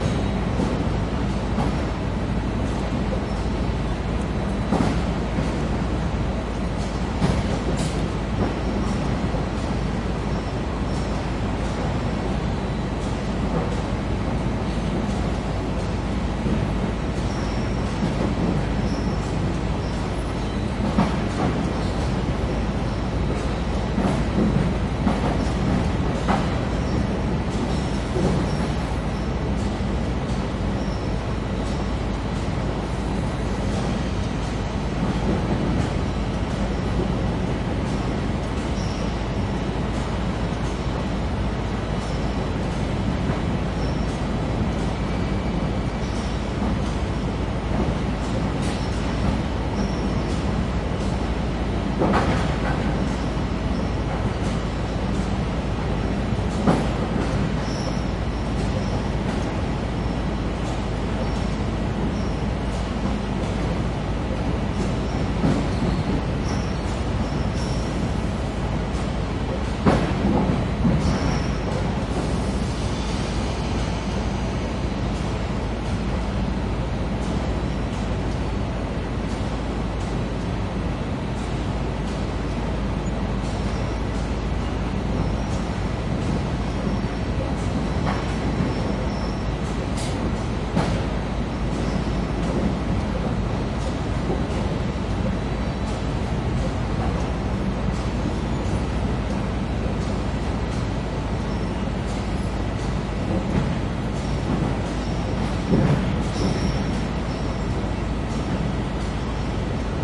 电梯现场记录与变焦h2n
标签： 技术 电动门 R 氛围 ELEKTRISCHE-T BAHNHOF 电梯 fahrstuhl 德语 德语 现场记录 列车站 噪声 放大H2N 城市
声道立体声